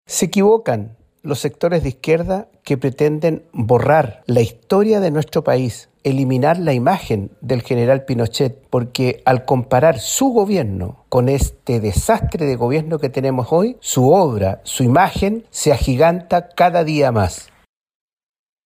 Por el contrario, el diputado de UDI, Sergio Bobadilla, expresó que Augusto Pinochet es parte de la historia e imagen de Chile.